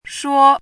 怎么读
shuō yuè shuì
shuo1.mp3